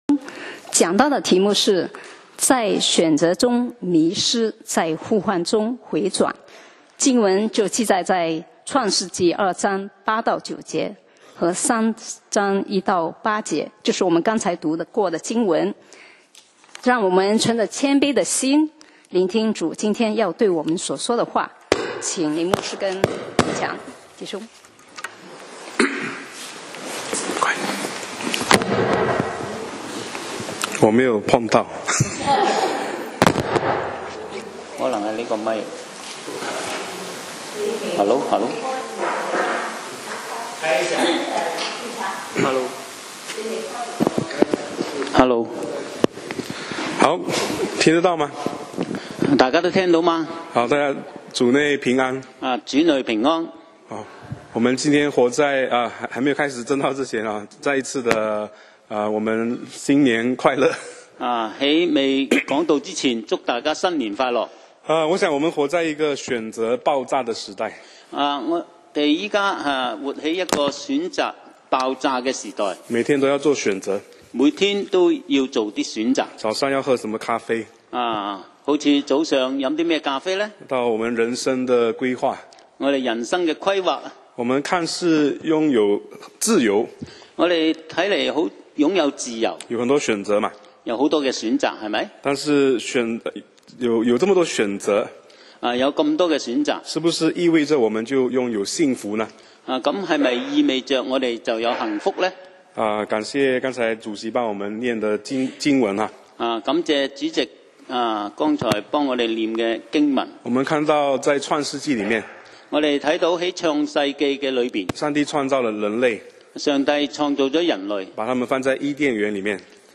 講道 Sermon 題目 在选择中迷失，在呼唤中回归 經文 Verses：創世紀 2:8-9+3:1-8。